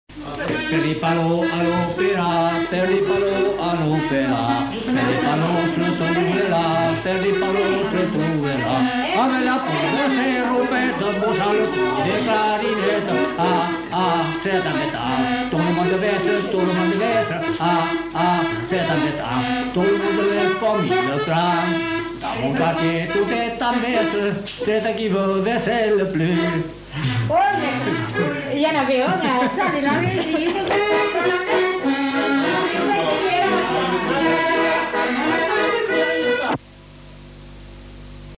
Aire culturelle : Grandes-Landes
Lieu : Luxey
Genre : chanson-musique
Type de voix : voix d'homme
Production du son : chanté
Instrument de musique : accordéon diatonique